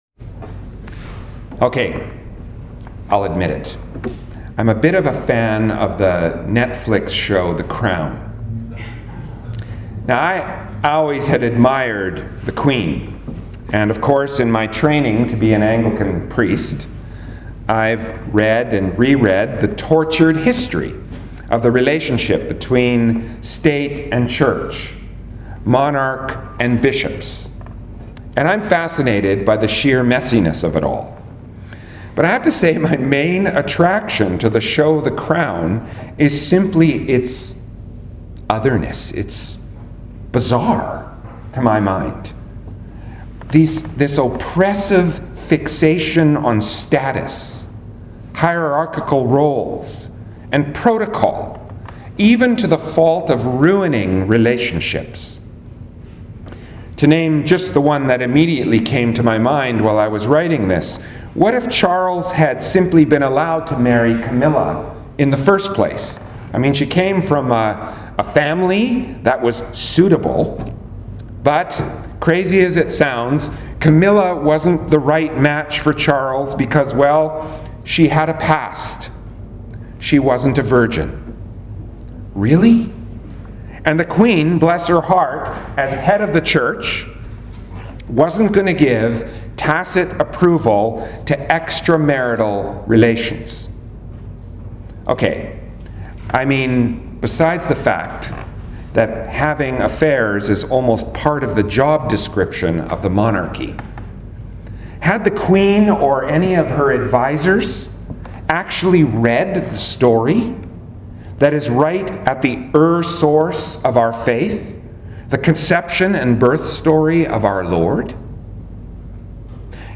Sermons | St. Thomas Anglican Church